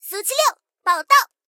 SU-76编入语音.OGG